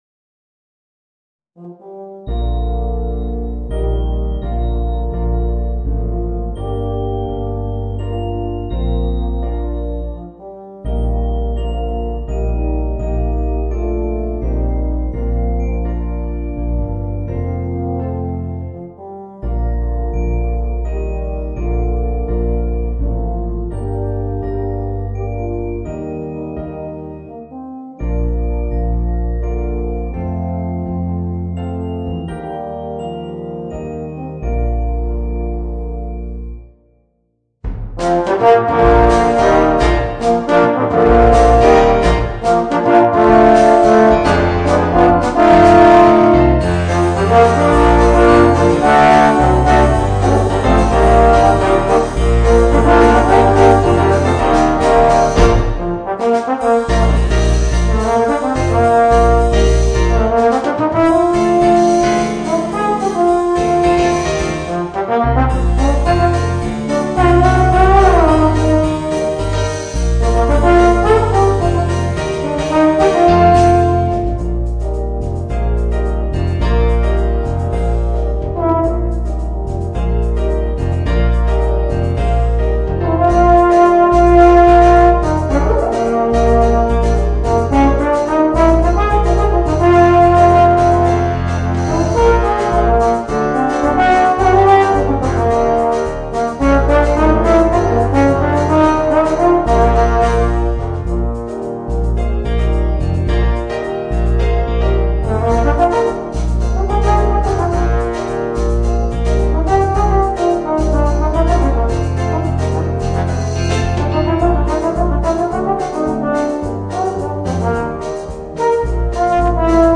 Voicing: 4 Euphoniums